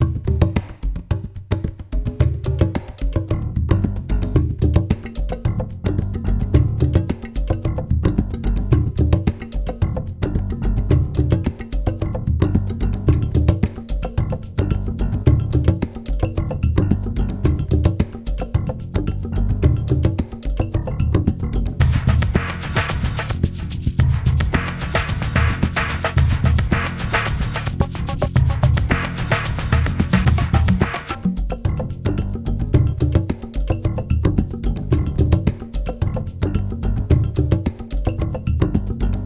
Ritmo de percusión 1 (bucle)
membranófono
tambor
africano
bongó
conga
latino
sintetizador